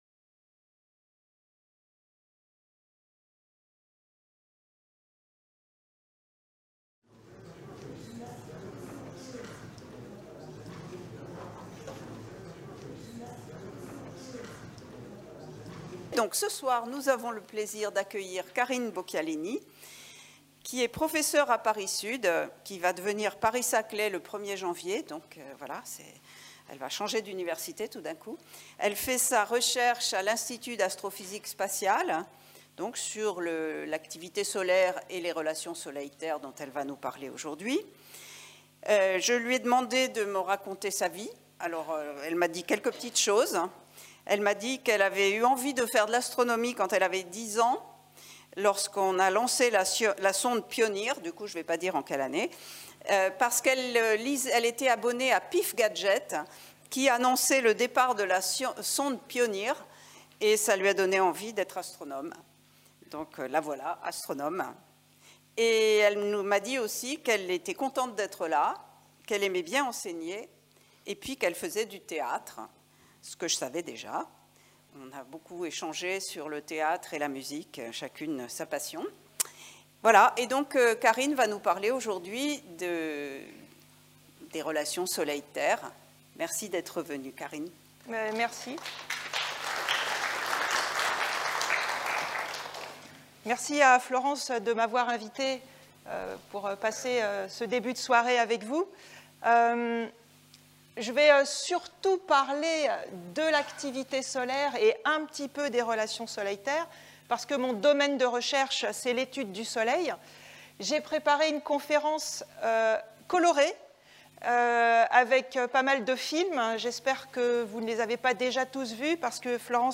Après une présentation générale du Soleil, la conférence se focalisera sur les phénomènes les plus spectaculaires qui caractérisent l'activité périodique de cette étoile « ordinaire », que la météorologie spatiale vise à prévoir. Les événements, plus ou moins violents, comme les éjections coronales de matière aux conditions physiques encore mal comprises, participent aux relations entre le Soleil et la Terre, dont les impacts sur notre planète peuvent être à la fois grandioses sous la forme des aurores polaires ou à risque pour l'activité technologique humaine.